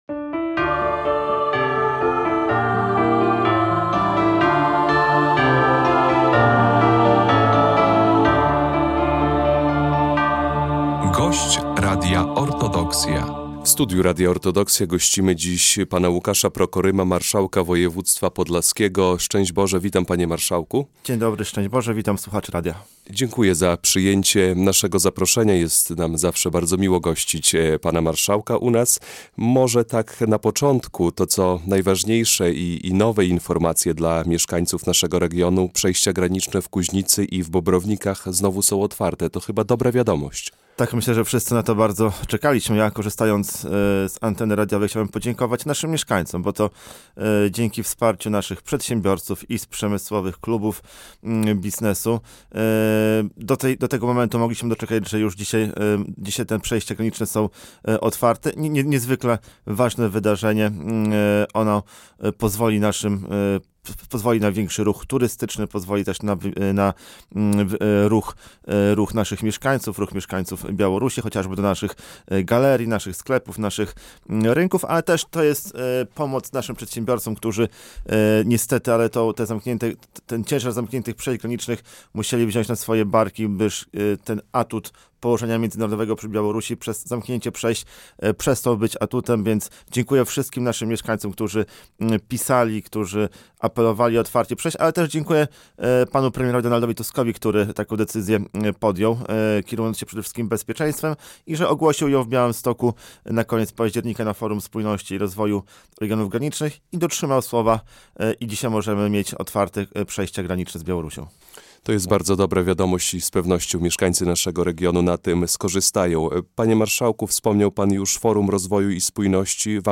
Podlaskie – rozmowa z Marszałkiem Łukaszem Prokorymem